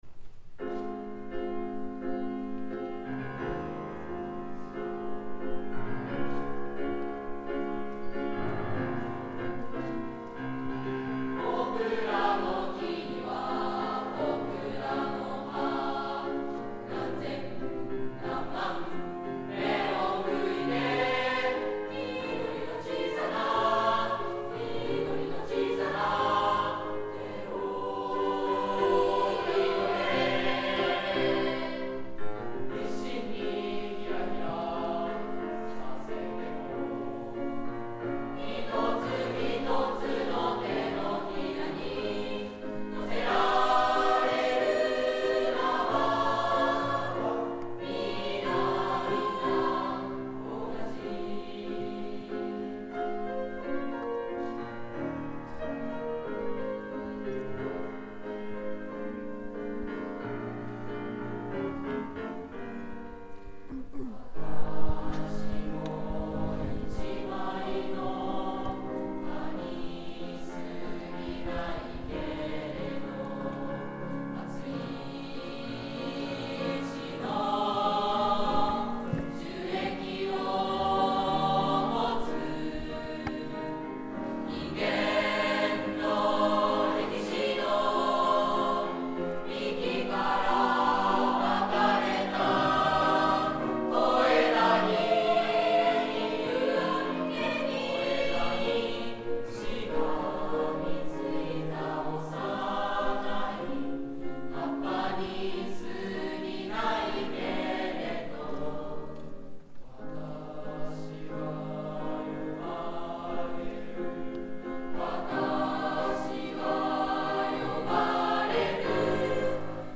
３Ｈ 名づけられた葉.mp3 ←クリックすると合唱が聴けます